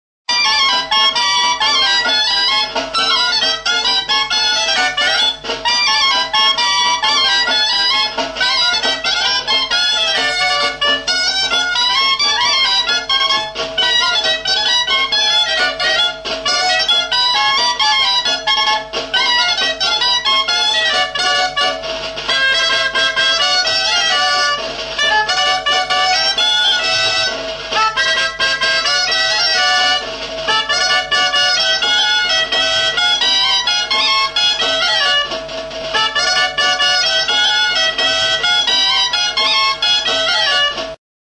DULTZAINA PITA
Aerófonos -> Lengüetas -> Doble (oboe)